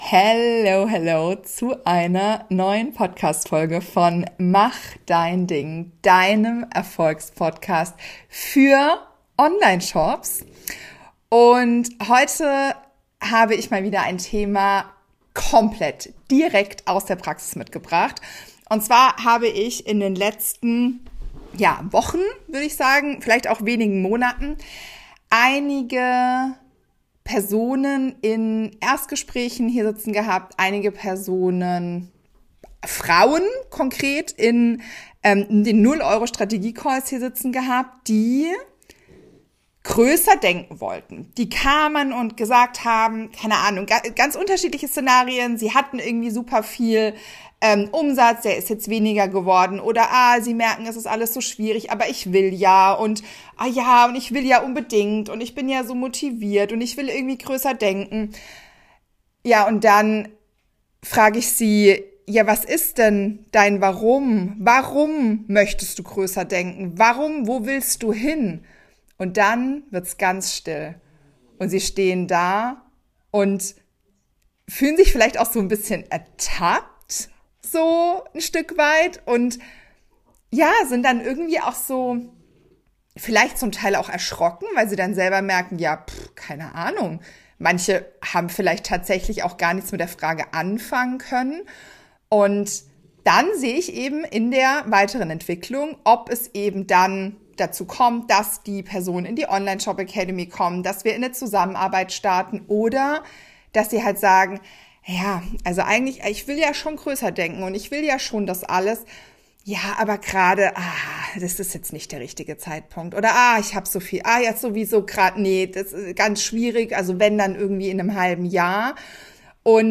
Ohne Warum kein Wachstum: Ein Interview